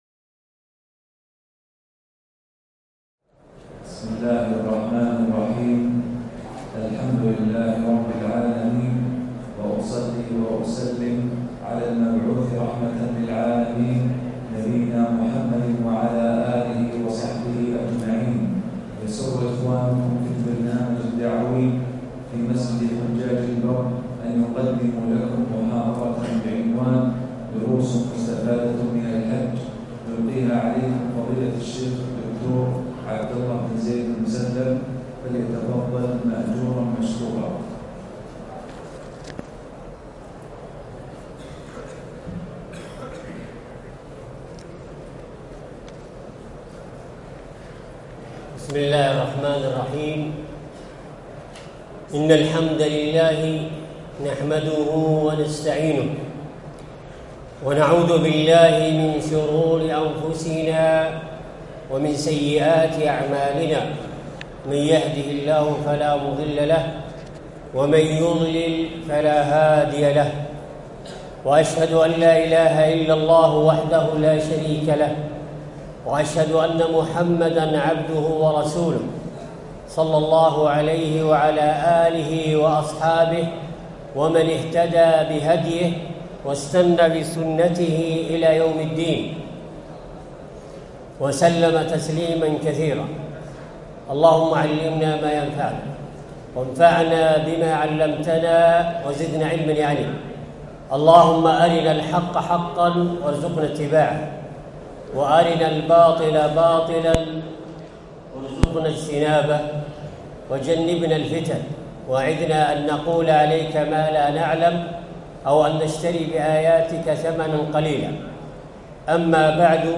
دروس مستفادة من الحج - محاضرات مسجد الخيف عام ١٤٣٩ هـ